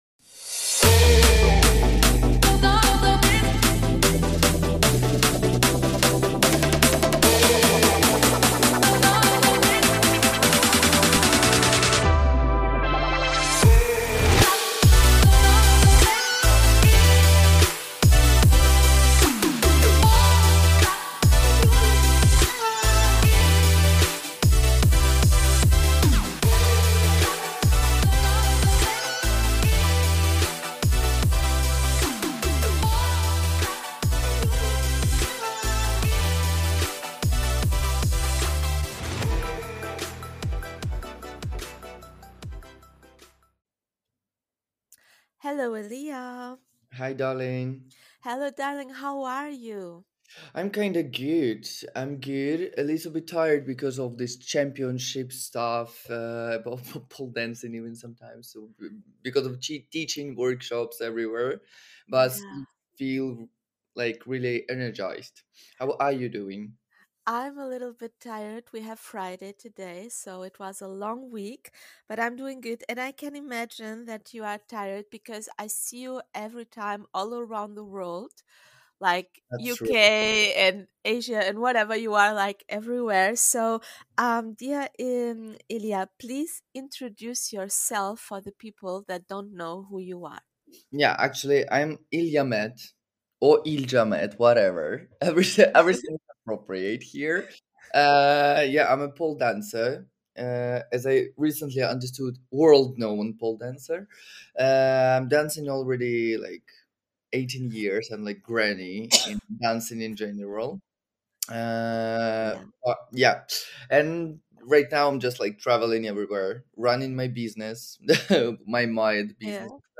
A conversation about art, bodies, and clothing as a statement.